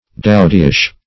Meaning of dowdyish. dowdyish synonyms, pronunciation, spelling and more from Free Dictionary.
dowdyish - definition of dowdyish - synonyms, pronunciation, spelling from Free Dictionary Search Result for " dowdyish" : The Collaborative International Dictionary of English v.0.48: Dowdyish \Dow"dy*ish\, a. Like a dowdy.